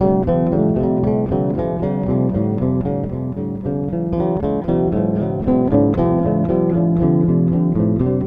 Initial Loop  (00:08)